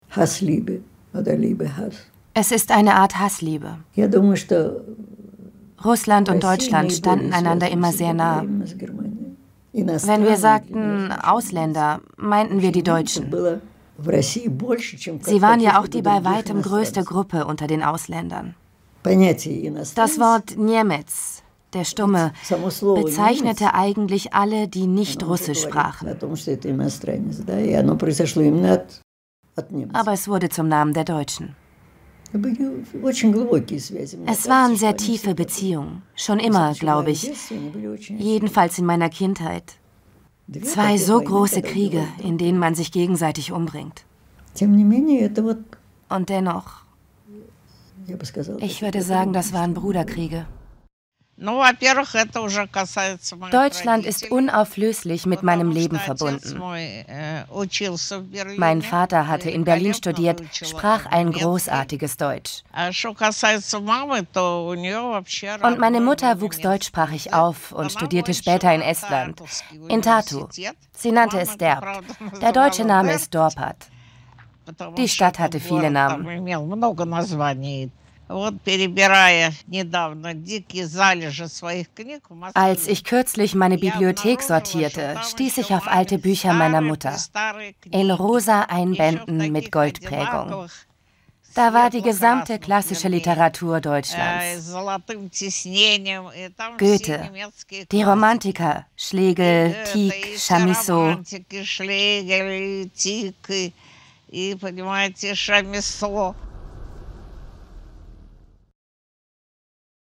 Sprechprobe: Sonstiges (Muttersprache):
Krieg und Frieden_Doku_0.mp3